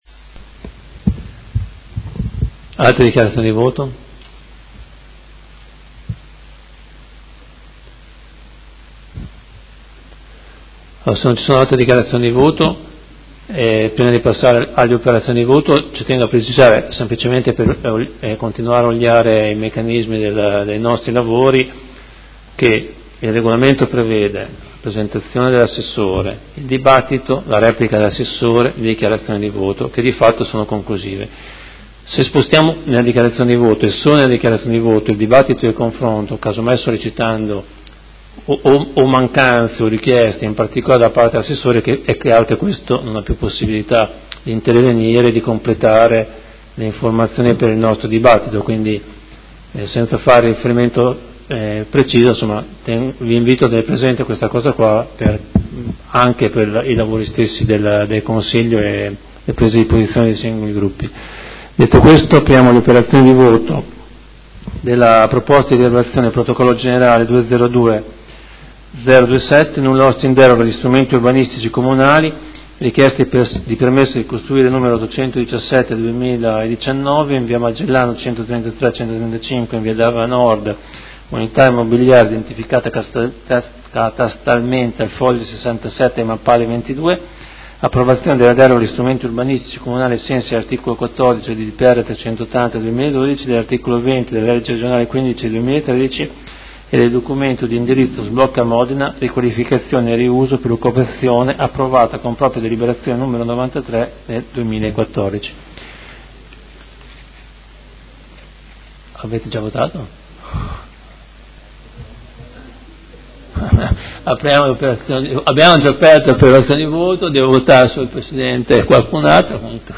Fabio Poggi — Sito Audio Consiglio Comunale
Seduta del 25/07/2019 Mette ai voti la delibera.